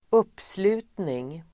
Ladda ner uttalet
Uttal: [²'up:slu:tning]